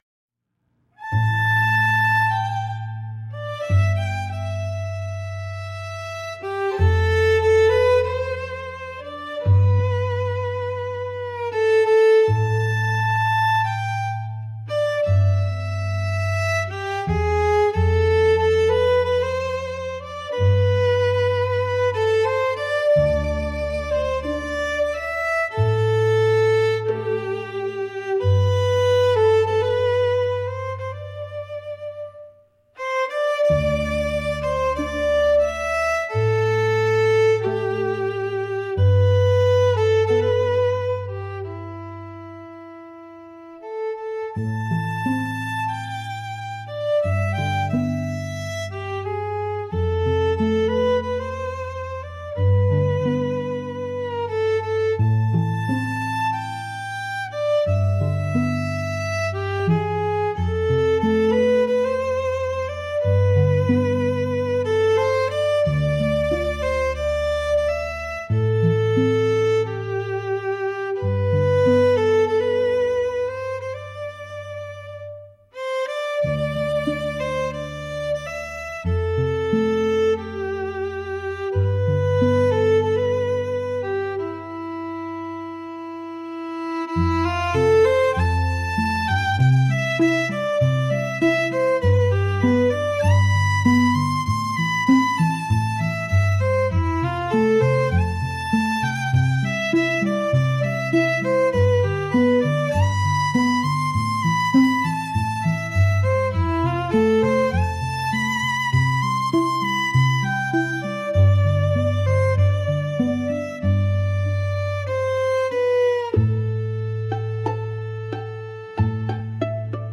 G Major – 94 BPM
Classical
Trap